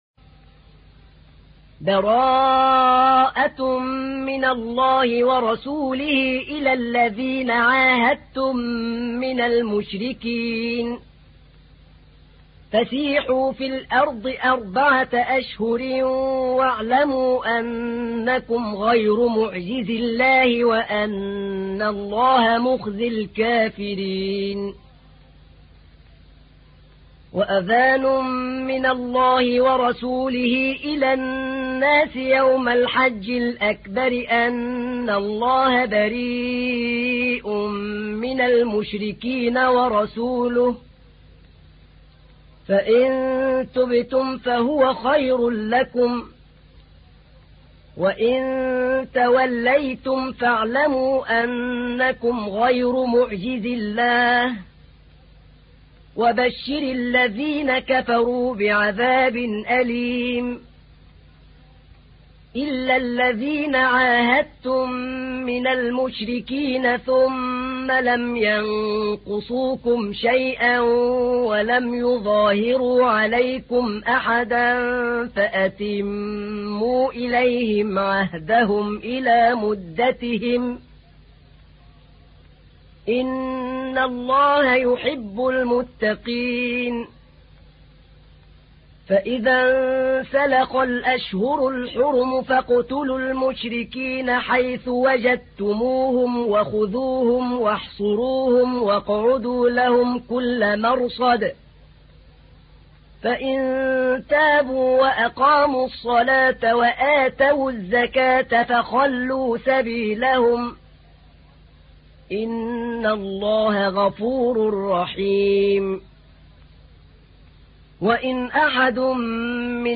تحميل : 9. سورة التوبة / القارئ أحمد نعينع / القرآن الكريم / موقع يا حسين